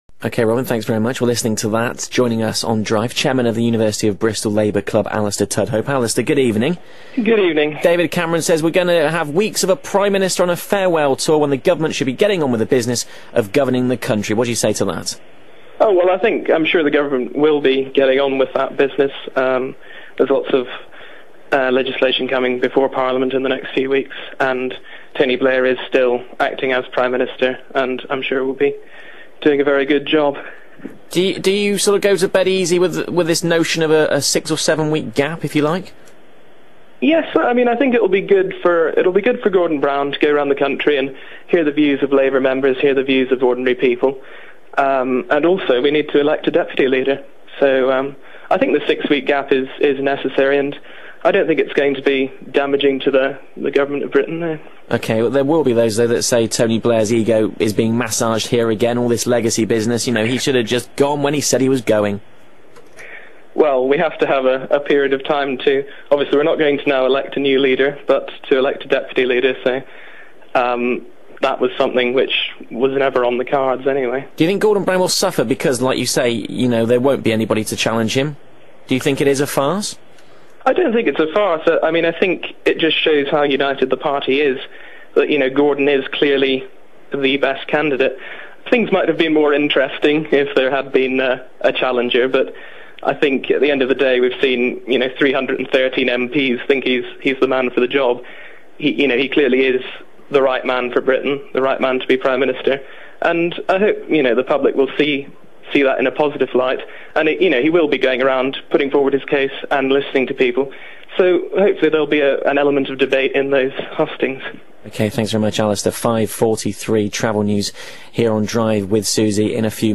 BBC Radio Bristol Interview